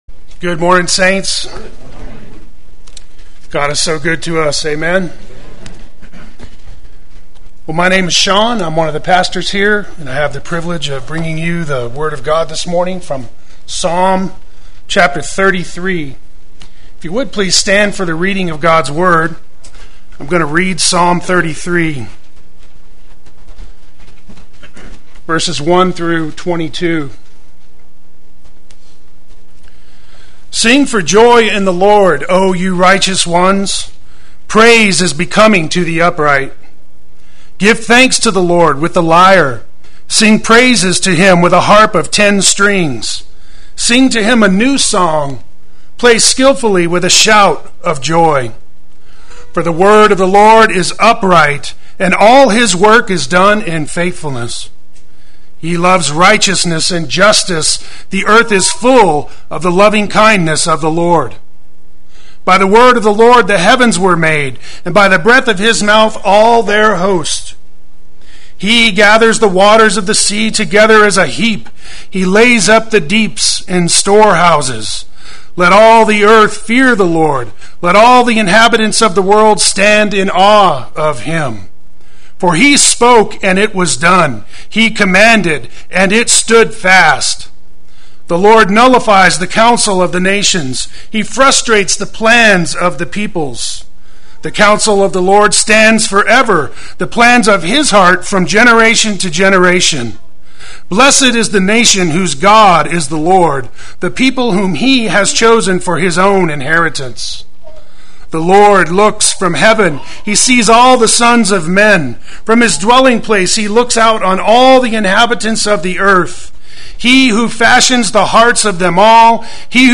Play Sermon Get HCF Teaching Automatically.
Sing for Joy to the Awesome God Sunday Worship